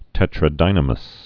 (tĕtrə-dīnə-məs)